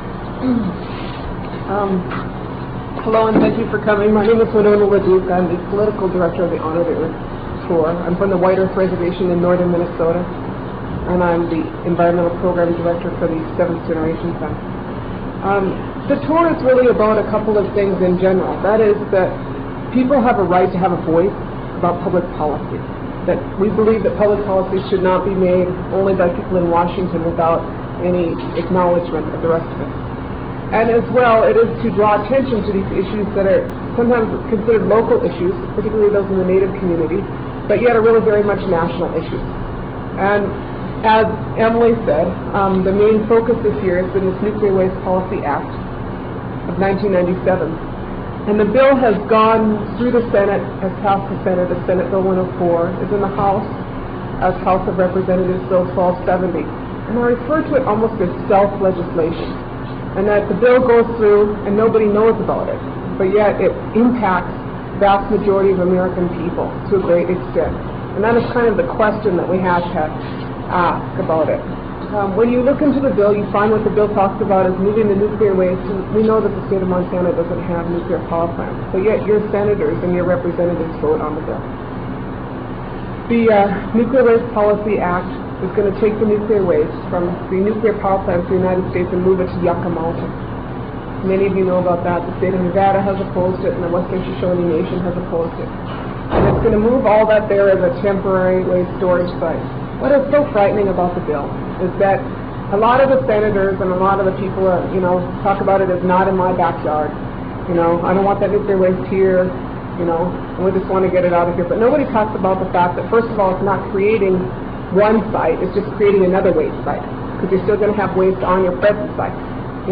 lifeblood: bootlegs: 1997-10-02: honor the earth - missoula, montana (press conference)
02. press conference - winona laduke (4:48)